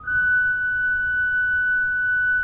Index of /90_sSampleCDs/Propeller Island - Cathedral Organ/Partition K/SWELL-WERK R